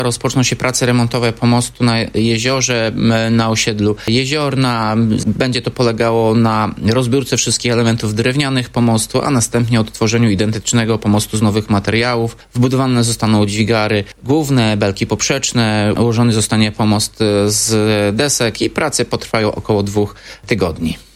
mówił Tomasz Andrukiewicz, prezydent miasta.